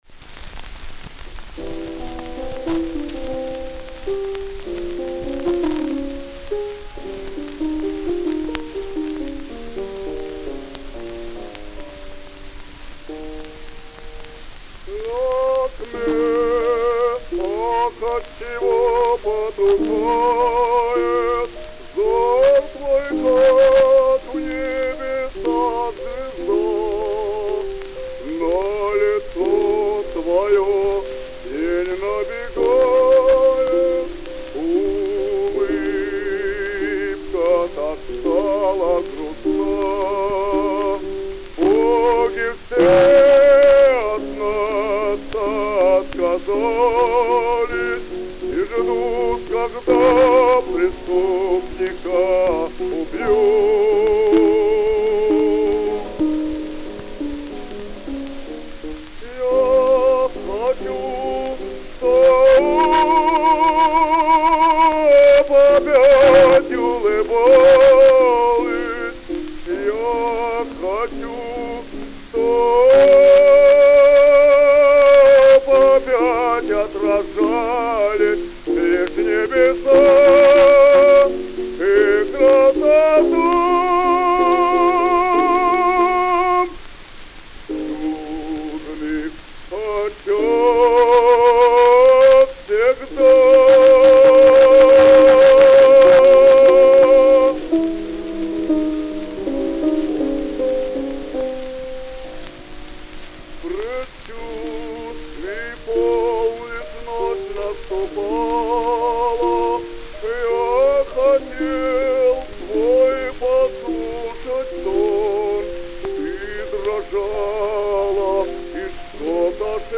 Обладал мощным богатого красками тембра с обширным верхним регистром, позволявшим петь помимо басовых партии для баритона; имел хорошие сценические данные.